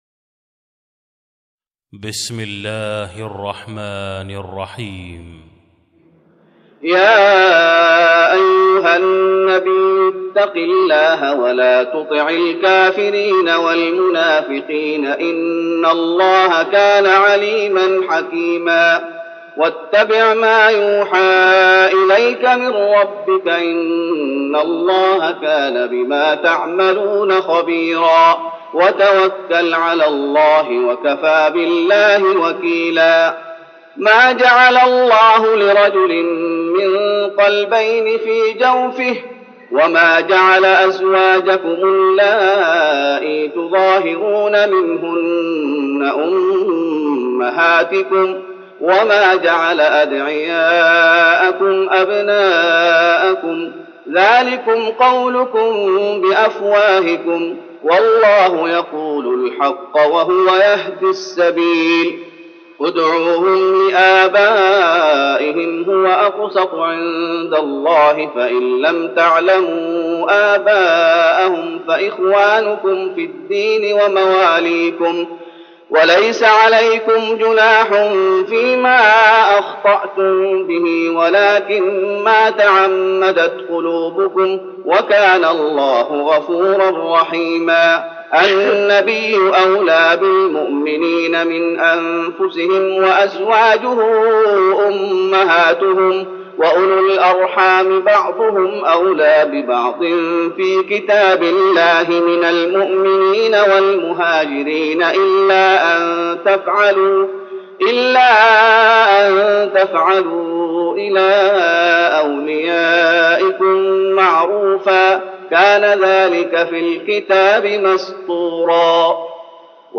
تراويح رمضان 1413هـ من سورة الأحزاب (1-34) Taraweeh Ramadan 1413H from Surah Al-Ahzaab > تراويح الشيخ محمد أيوب بالنبوي 1413 🕌 > التراويح - تلاوات الحرمين